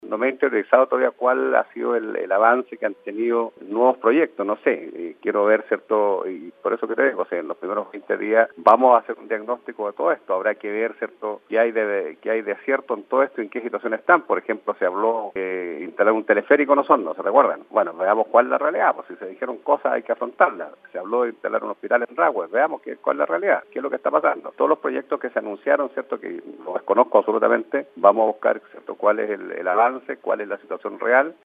En conversación con Radio Bío Bío, el alcalde electo dijo que desconoce el avance que han tenido los nuevos proyectos en la comuna, como el teleférico para Osorno y el Hospital en Rahue.
alcalde-osorno.mp3